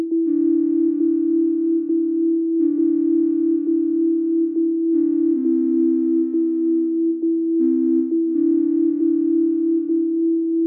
drone.ogg